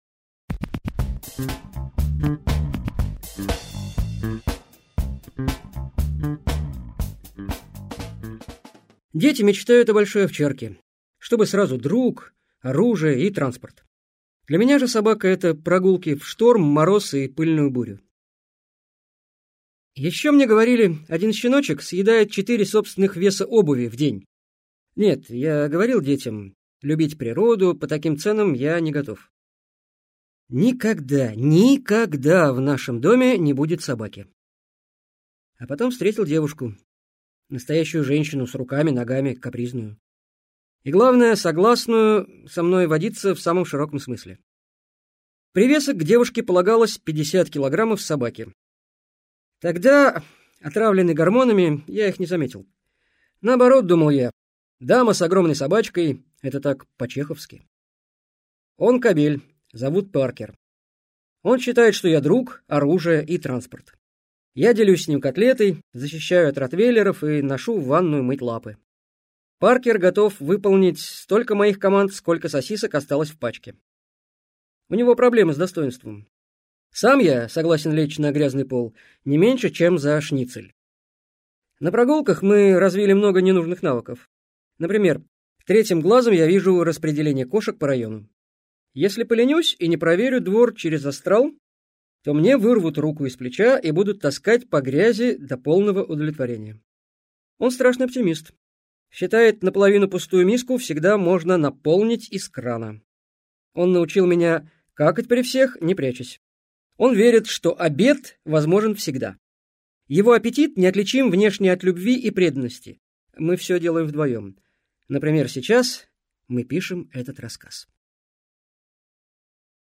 Аудиокнига Сантехник с пылу и с жаром | Библиотека аудиокниг